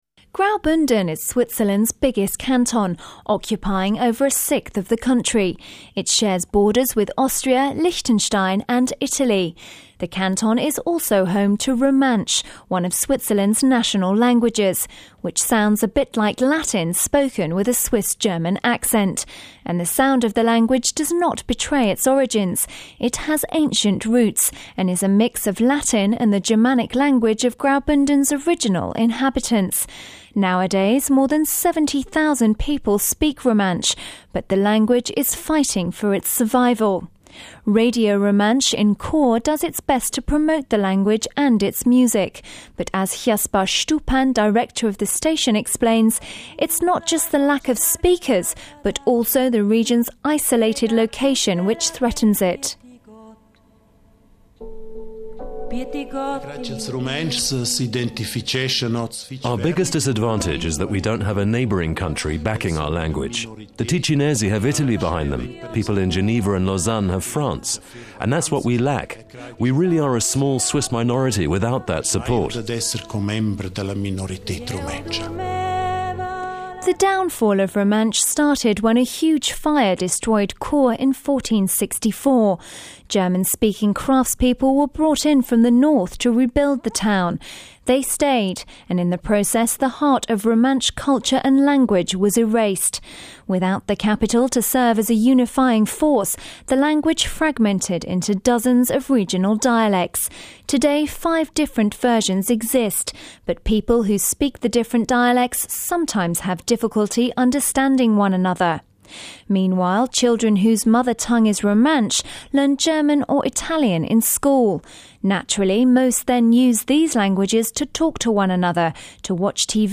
Switzerland has three official federal languages: French, German and Italian, as well as one semi-official language, Romansch (prono: Rum-ansh). Often described as sounding like "Latin with a Swiss-German accent," Romansch is spoken mainly in the eastern canton of Graubünden (prono: Grau-BOON-den). Although it is spoken by roughly 70,000 people in Switzerland, analysts predict that the language may die out by 2030 if steps aren’t taken to preserve it.